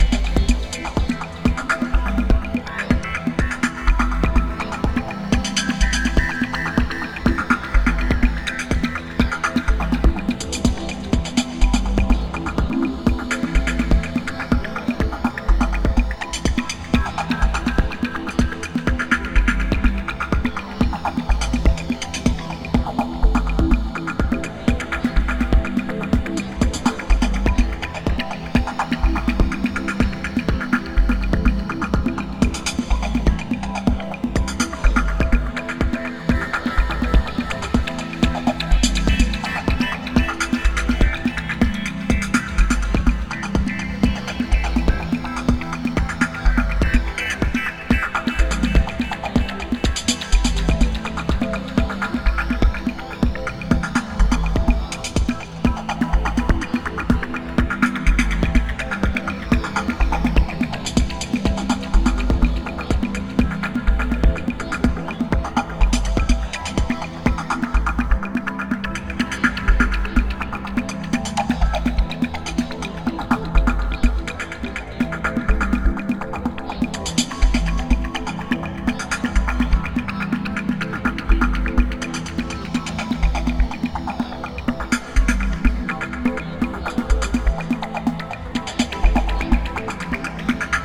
アクアティックなイメージを運ぶ煌めいたダブと柔らかなパーカッションが美しいアンビエント感覚のB2。